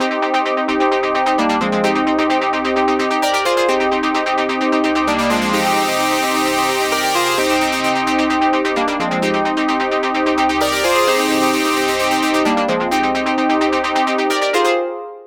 FILTER BED 5.wav